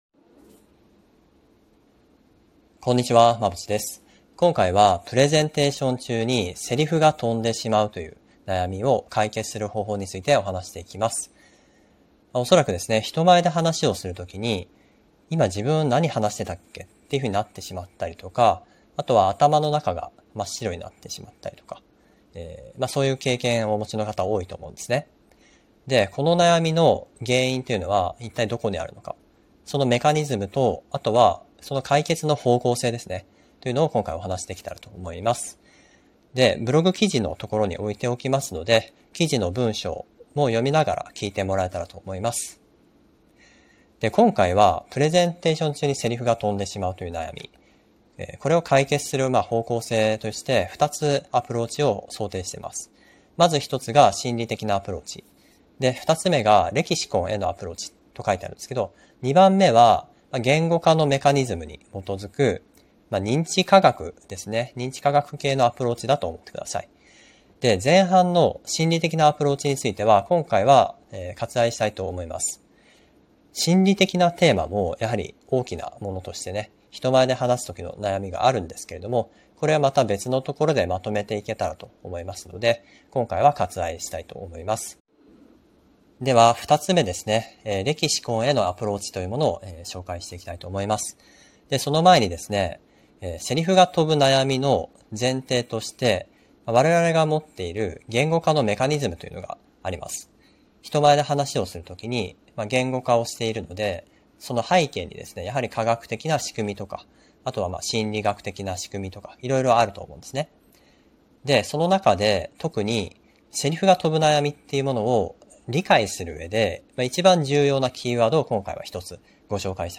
↓↓ 【音声解説】プレゼンでセリフが飛んでしまう悩みを解決する方法！